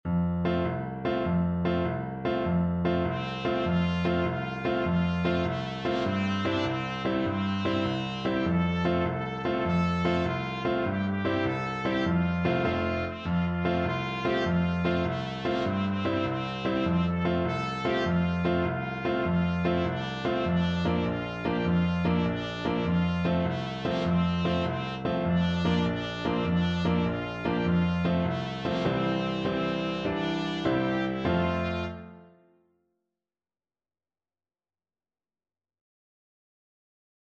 Allegro .=c.100 (View more music marked Allegro)
6/8 (View more 6/8 Music)
Traditional (View more Traditional Trumpet Music)